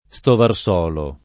[ S tovar S0 lo ]